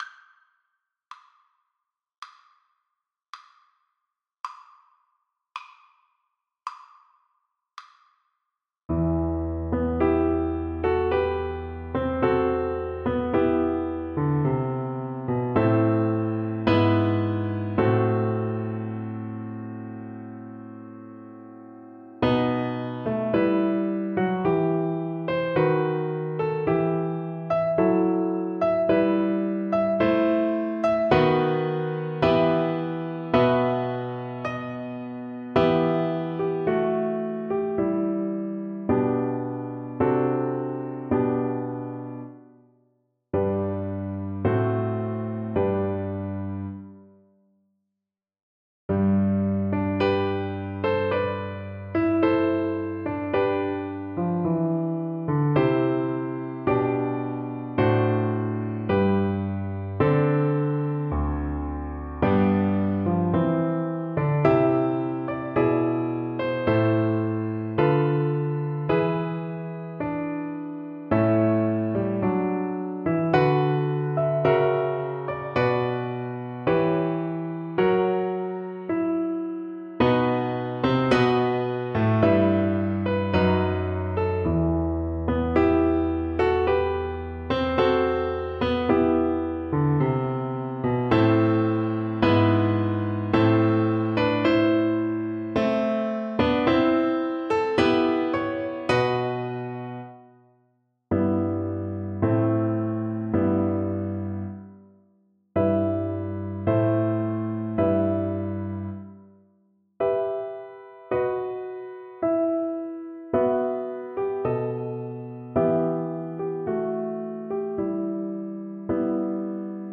Play (or use space bar on your keyboard) Pause Music Playalong - Piano Accompaniment Playalong Band Accompaniment not yet available transpose reset tempo print settings full screen
G major (Sounding Pitch) (View more G major Music for Viola )
4/4 (View more 4/4 Music)
=54 Adagio cantabile (View more music marked Adagio cantabile)
Viola  (View more Intermediate Viola Music)
Classical (View more Classical Viola Music)